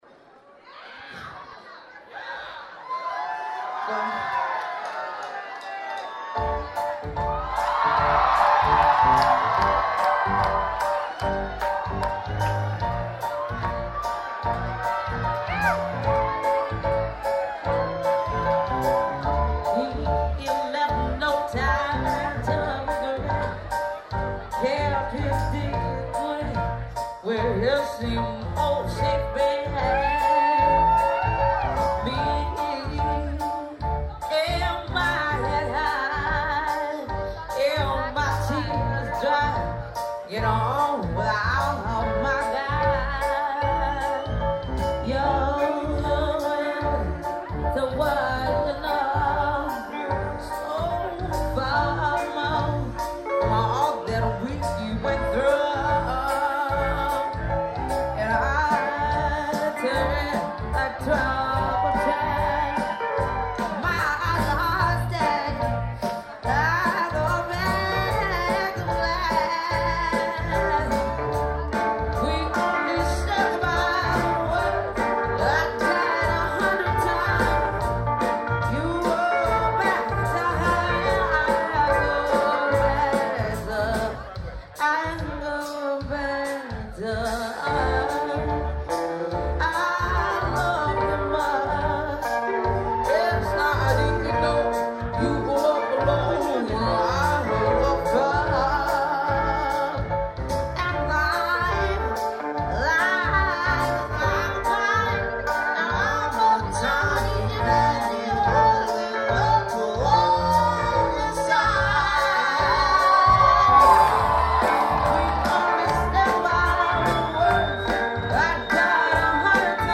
This track comes from her performance in Toronto last week.